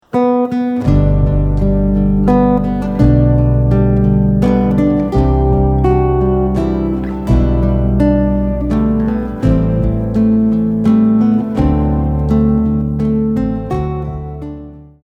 🎵 Melody with chords backing track
🎵 Full guitar arrangement backing track